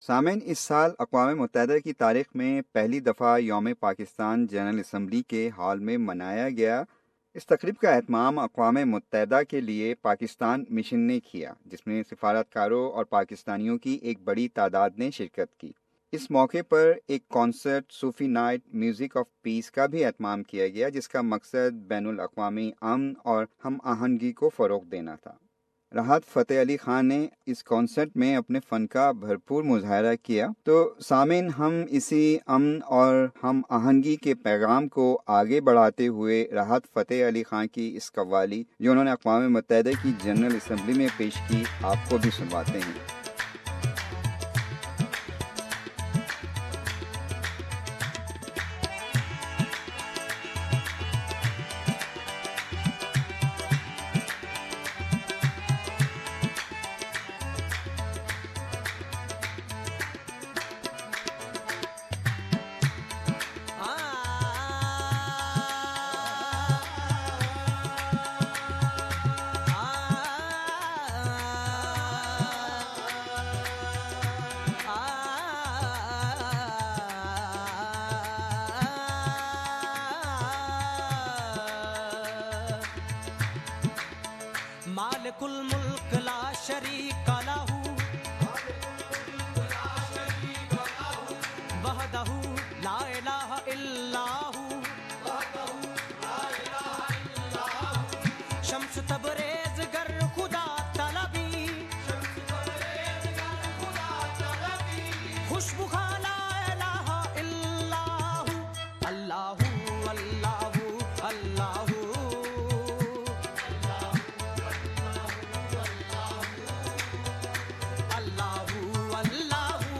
The Magical Voice.